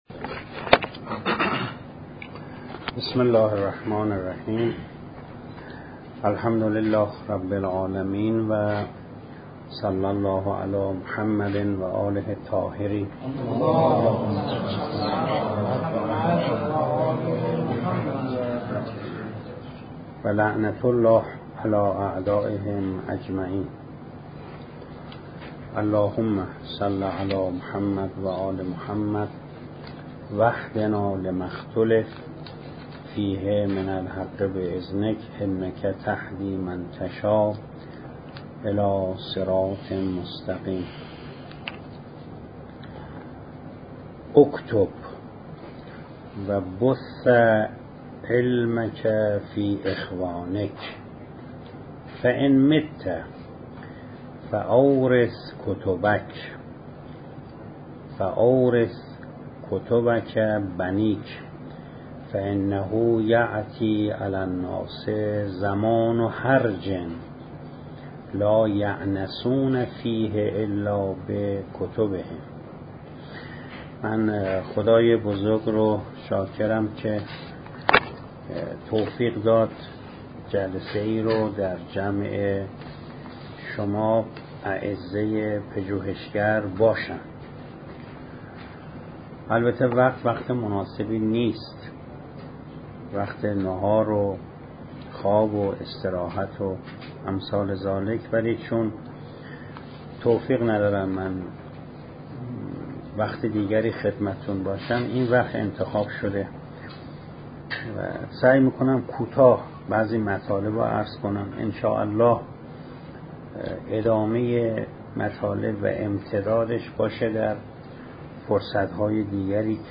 فلسفه فقه (بایسته های مفهوم شناسی در نصوص دینی) - جلسه پنجم - چیذر حوزه قائم